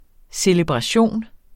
Udtale [ seləbʁɑˈɕoˀn ]